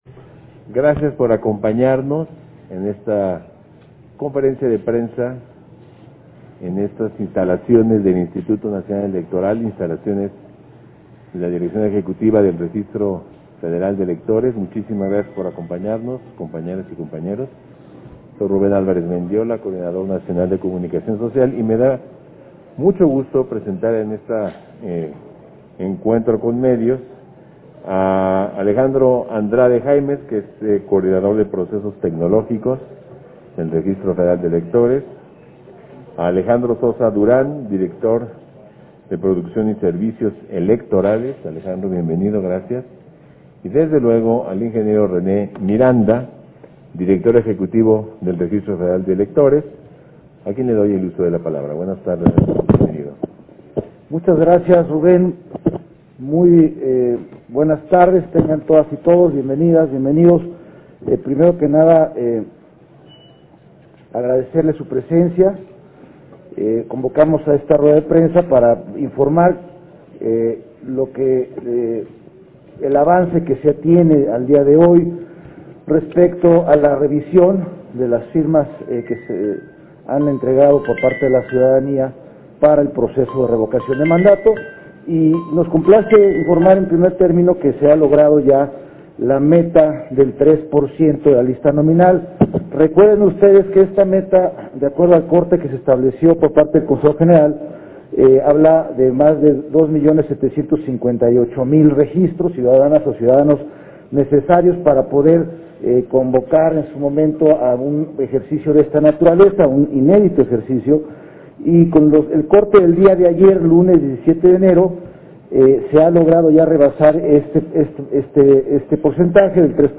180122_AUDIO_CONFERENCIA-DE-PRENSA